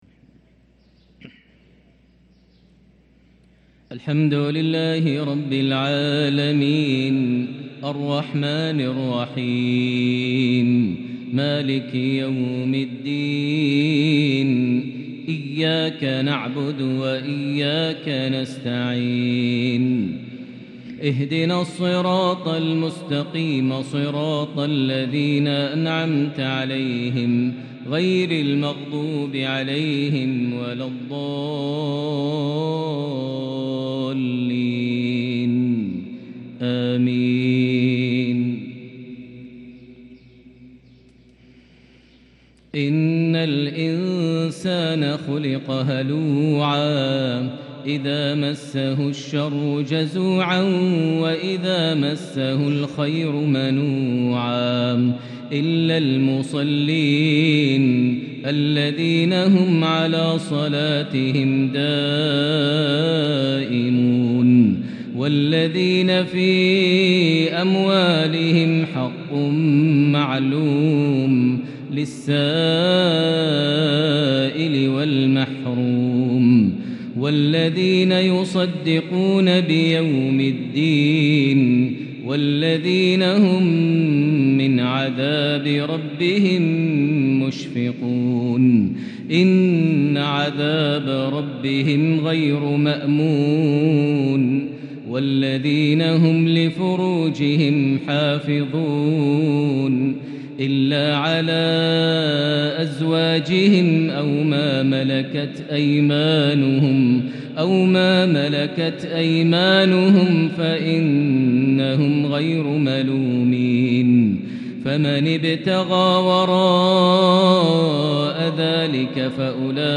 Maghrib prayer from Surah Al Ma’arej 3-1-2023 > 1444 H > Prayers - Maher Almuaiqly Recitations